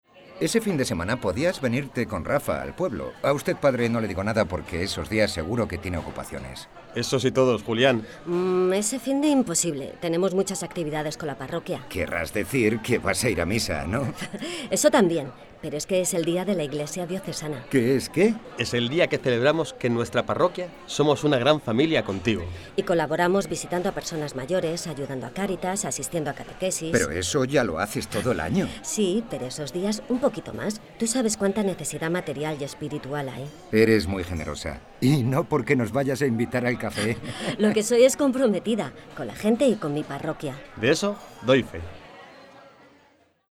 Cuñas de Radio -  Día de la Iglesia Diocesana 2019